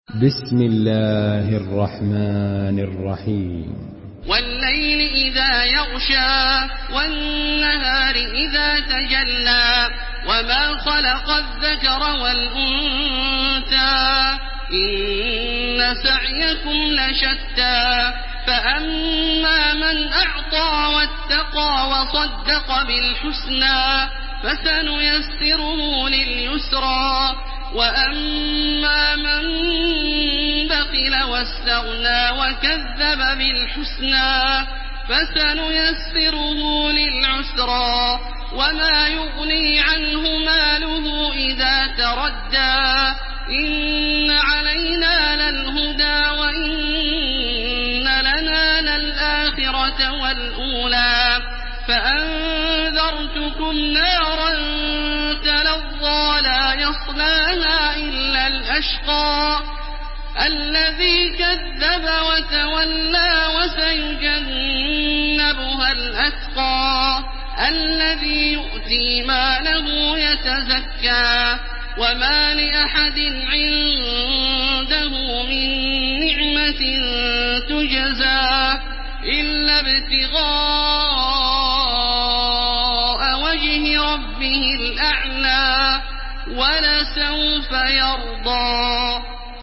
Surah Al-Layl MP3 by Makkah Taraweeh 1430 in Hafs An Asim narration.
Murattal